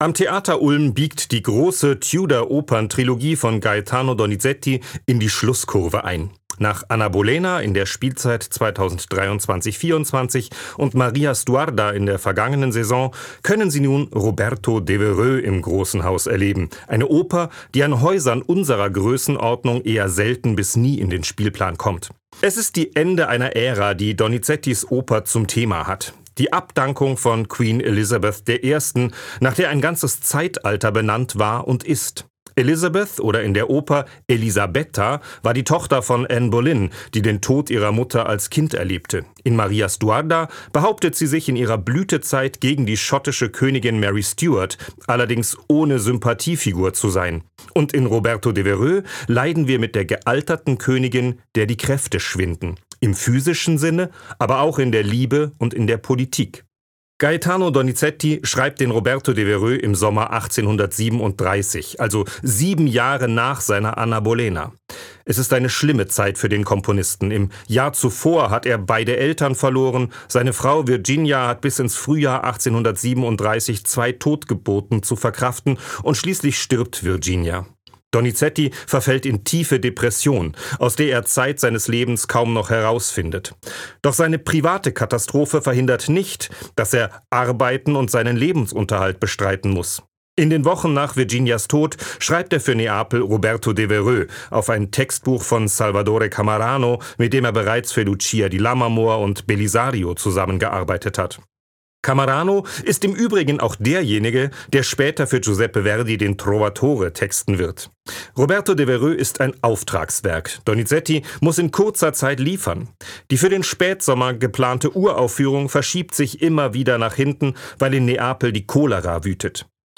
Höreinführung